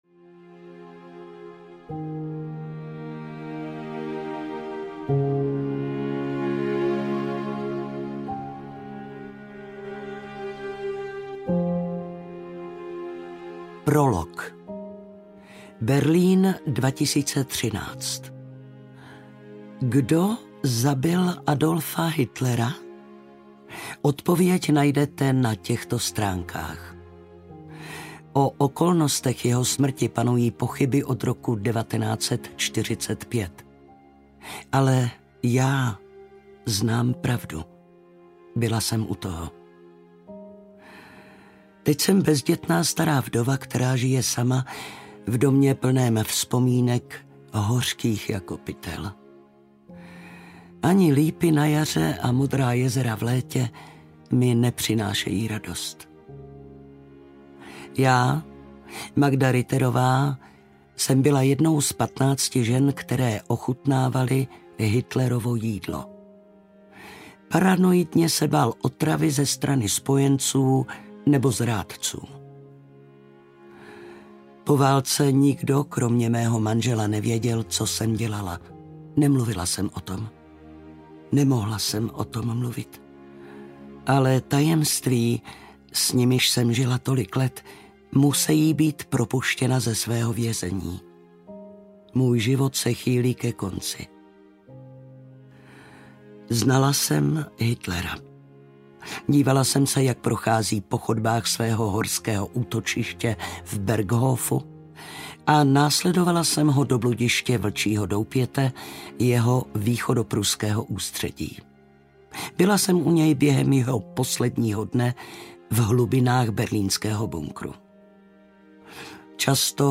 Ochutnávačka audiokniha
Ukázka z knihy
• InterpretValérie Zawadská